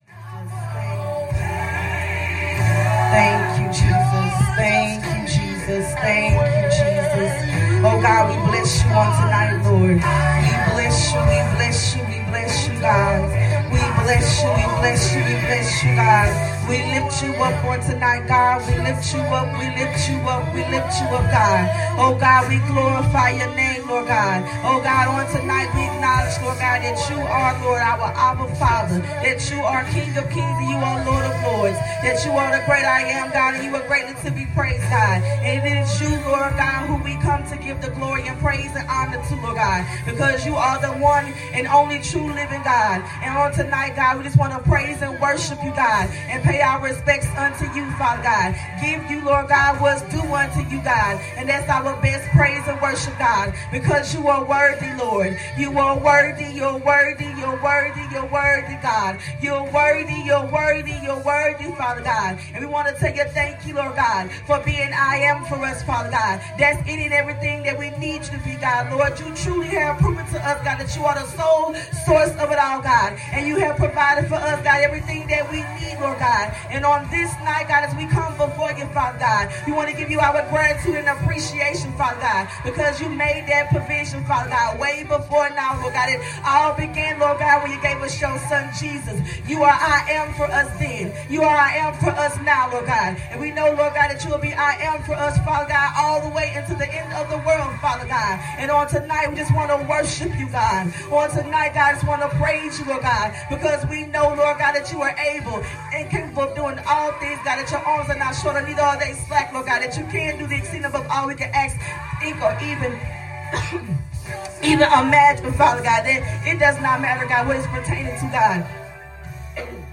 Breakthrough Service Prayer 4.18.25